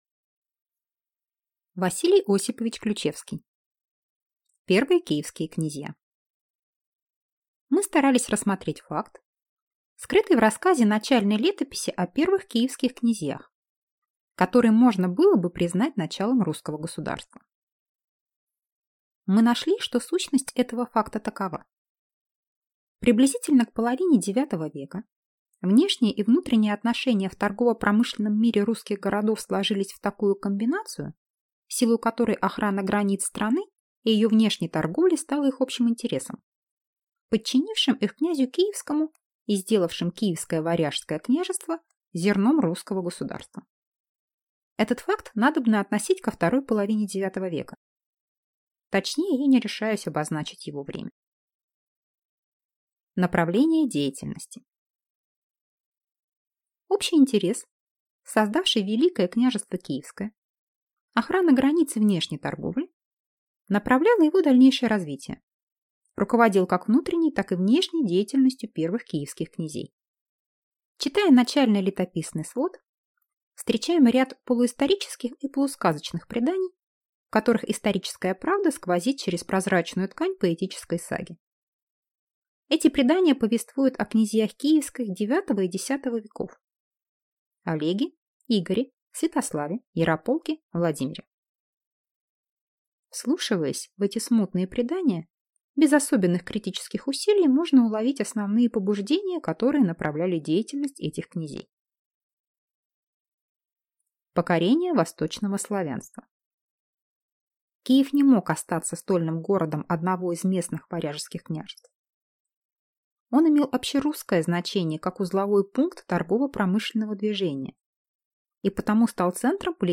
Аудиокнига Первые Киевские князья | Библиотека аудиокниг